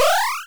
collect_b.wav